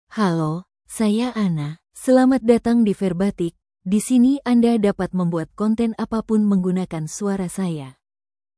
FemaleIndonesian (Indonesia)
AnnaFemale Indonesian AI voice
Voice sample
Female
Anna delivers clear pronunciation with authentic Indonesia Indonesian intonation, making your content sound professionally produced.